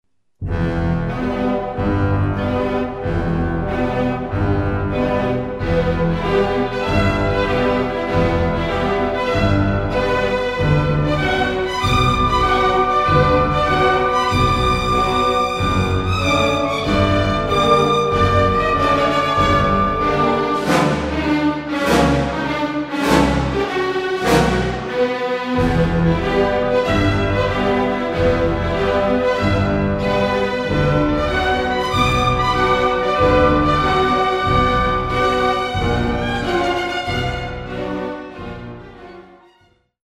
Das Ballett
Klassik-CDs Ballettmusik